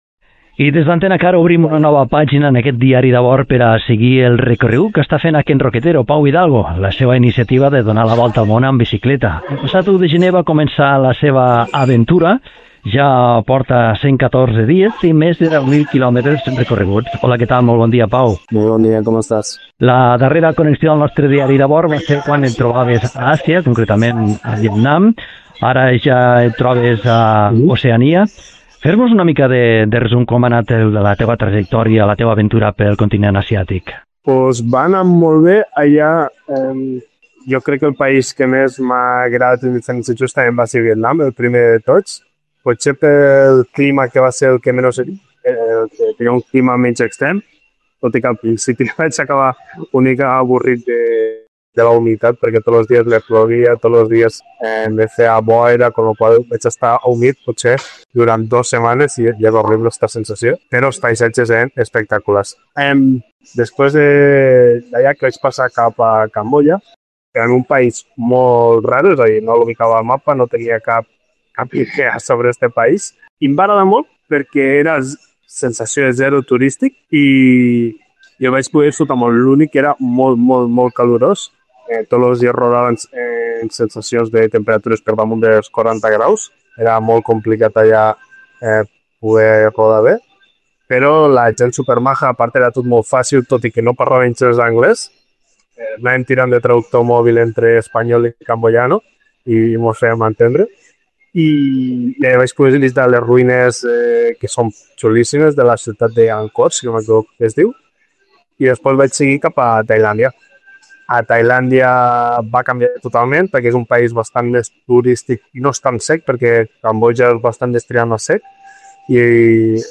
per videoconferència